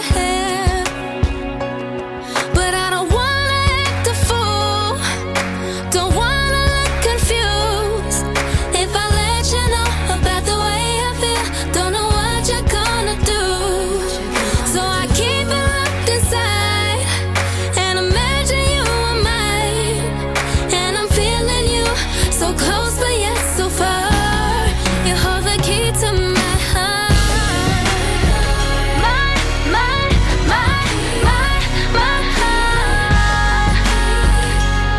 # Traditional Pop